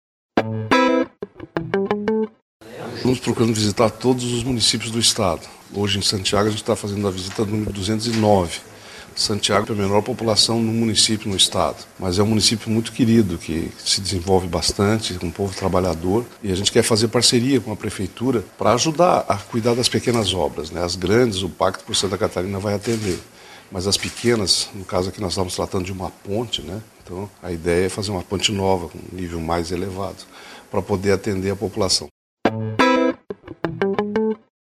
Ouça a entrevista com o governador Raimundo Colombo: